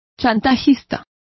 Complete with pronunciation of the translation of racketeers.